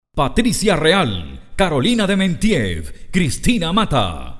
Locutor comercial, E learning y corporativo versátil
Sprechprobe: Sonstiges (Muttersprache):